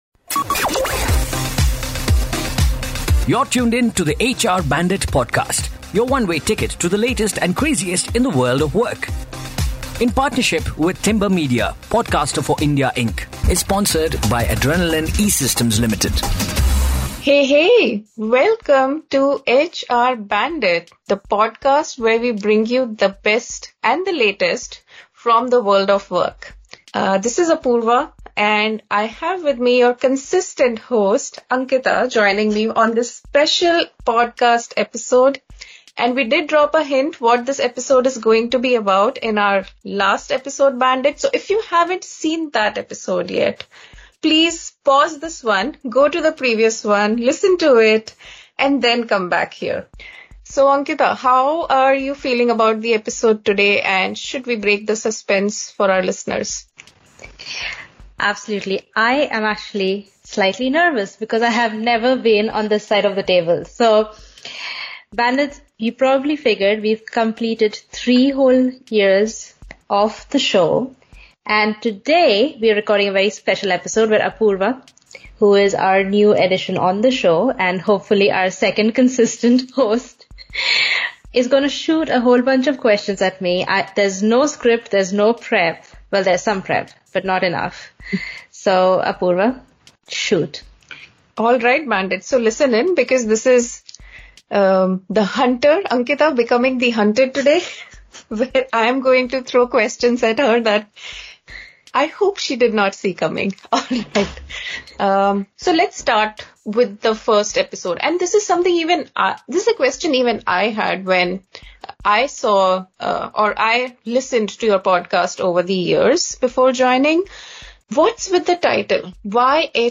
You heard us singing "Happy Birthday," and now it's time for a very special occasion on HR Bandit!